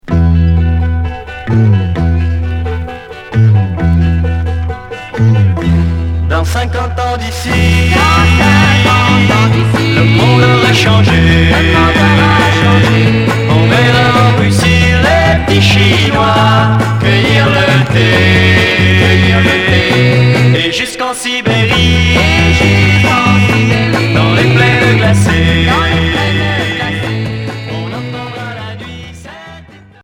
Pop psyché à sitar